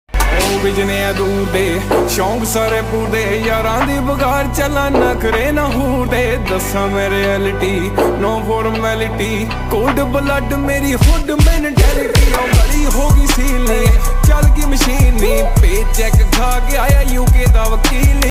lofi ringtone